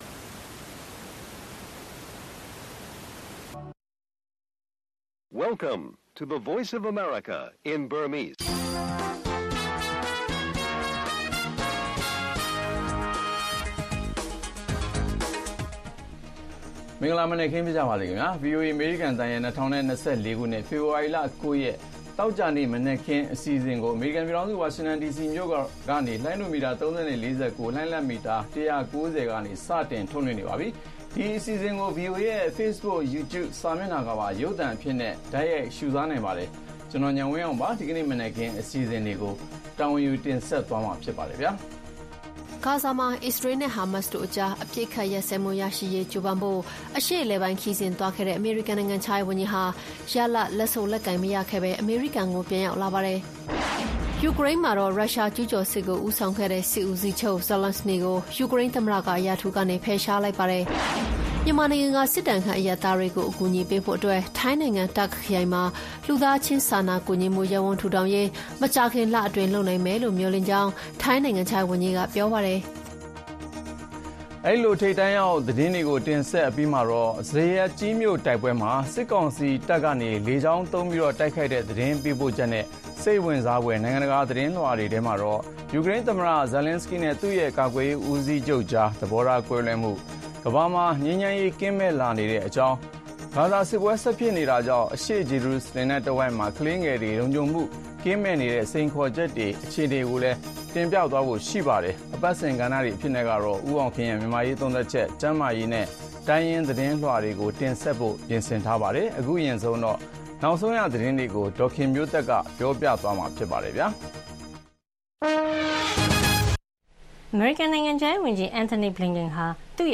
ဗွီအိုအေမြန်မာနံနက်ခင်း(ဖေဖော်ဝါရီ ၉၊၂၀၂၄) ကန်နိုင်ငံခြားရေးဝန်ကြီးရဲ့ ဂါဇာအရေးညှိုနှိုင်းမှုခရီး ရလာဒ်မထွက်ခဲ့၊ ဇရပ်ကြီးမြို့တိုက်ပွဲ စစ်ကောင်စီလေကြောင်းသုံးတိုက်ခိုက် စတဲ့သတင်းတွေနဲ့ အပတ်စဉ်အစီအစဉ်တွေကို ထုတ်လွှင့်တင်ဆက်ပါမယ်။